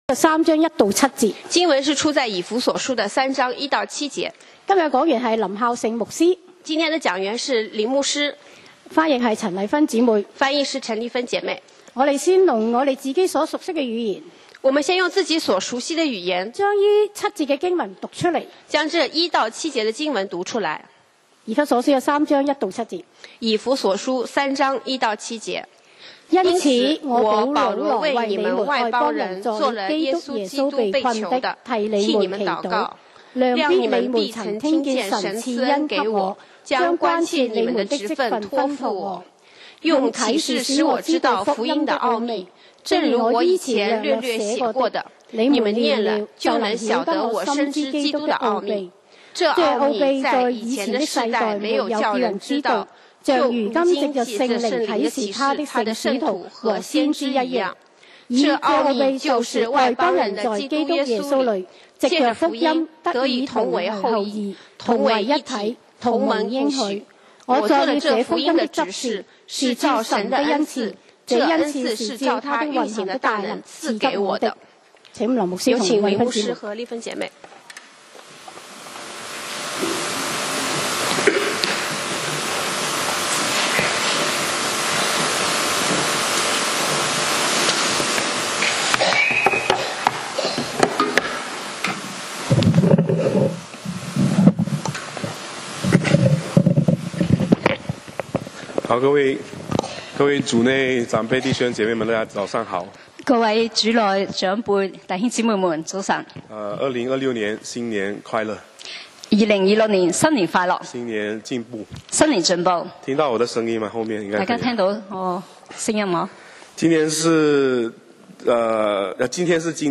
講道 Sermon 題目 Topic： 計劃 經文 Verses：以弗所書 3:1-7. 1.因此，我─保羅為你們外邦人作了基督耶穌被囚的，替你們祈禱（此句乃照對十四節所加）。